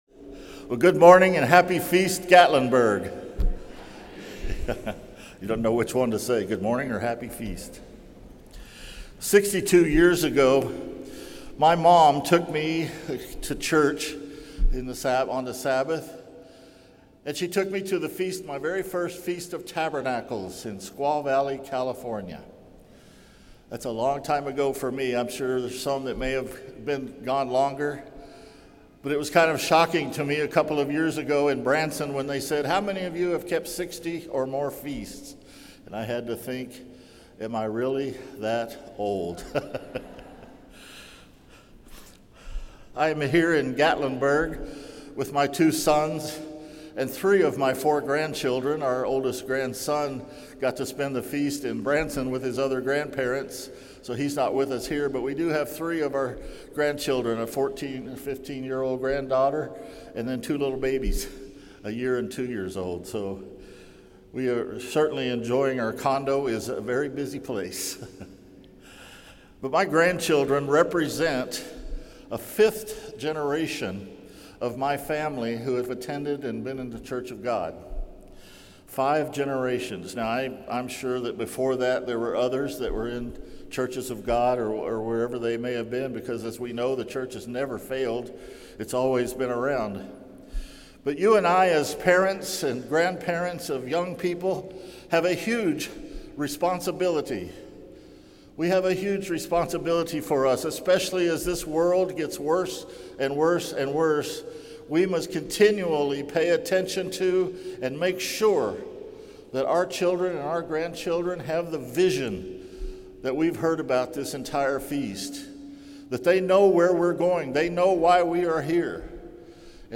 This sermon was given at the Gatlinburg, Tennessee 2024 Feast site.